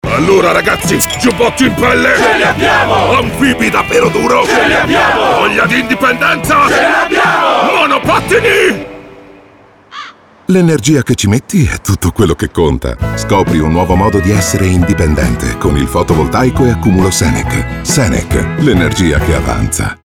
È stato avviato oggi, e durerà per le prossime tre settimane, il nuovo spot radiofonico di Senec.